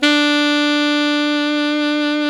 SAX ALTOMF07.wav